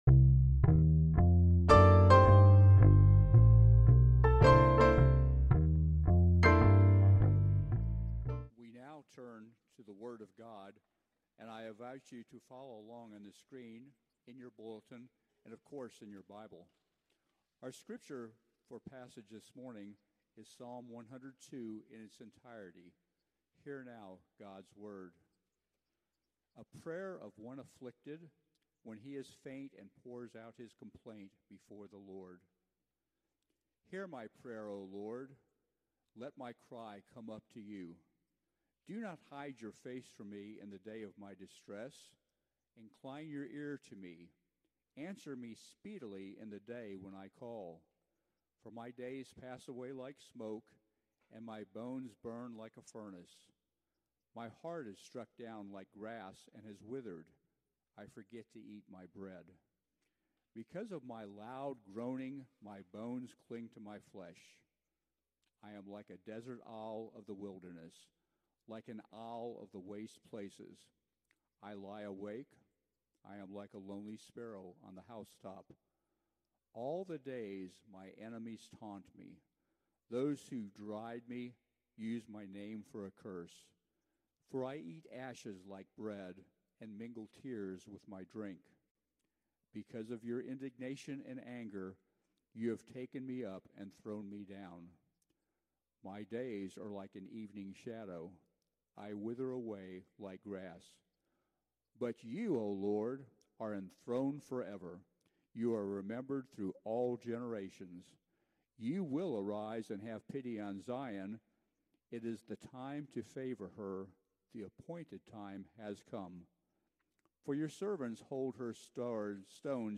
Passage: Psalm 102 Service Type: Sunday Worship
NAPC_Sermon_6.9.24.mp3